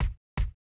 foot_1.wav